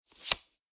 カードめくり1.mp3